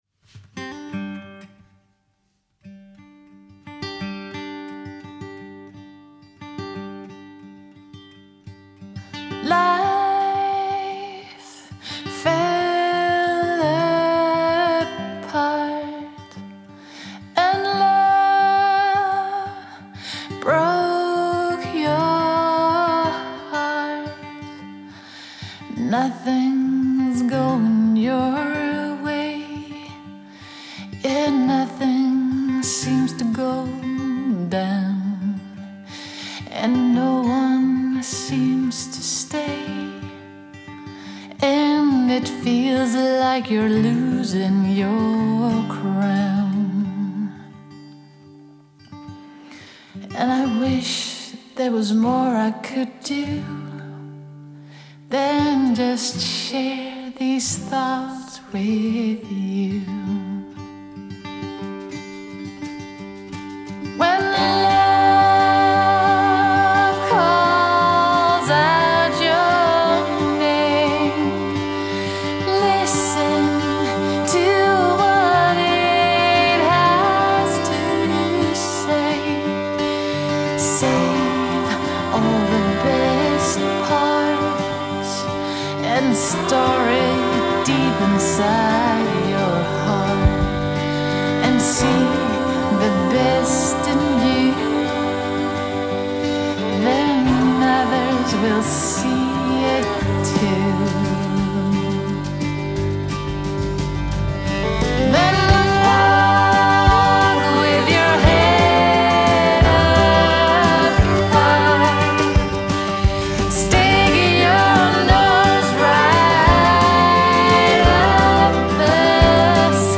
Guitar, Upright Bas, Mandolin, Violin, and Dobro.